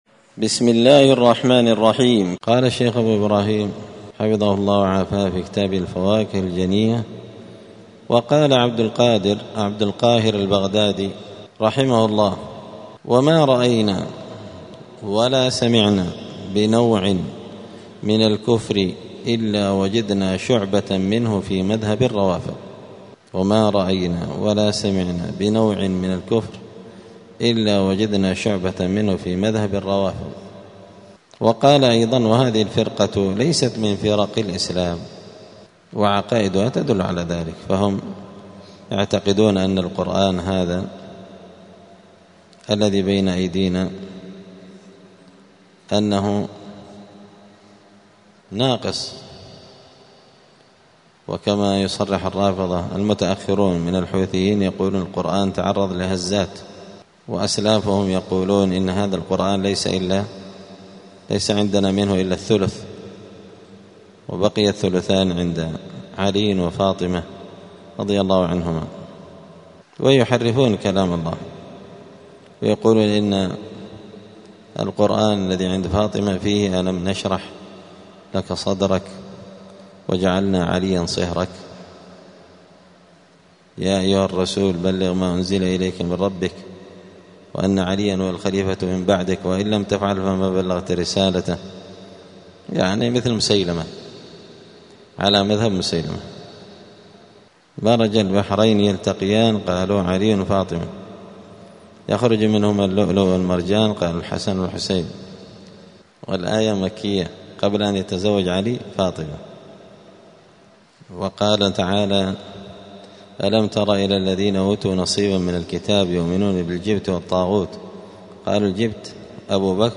دار الحديث السلفية بمسجد الفرقان بقشن المهرة اليمن
*الدرس الخامس والثمانون (85) {من أسباب الهداية إلى الحق}.*